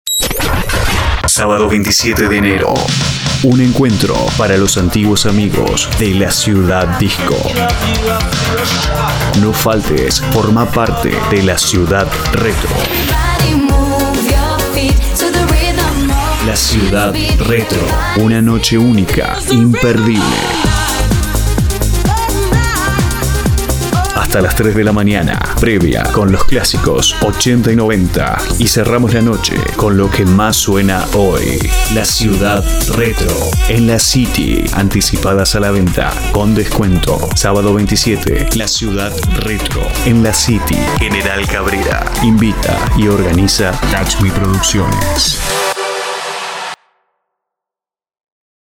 Mic AT 2500 USB Edit SoundForge Pro 11 Adobe Audition 6
spanisch Südamerika
Sprechprobe: Sonstiges (Muttersprache):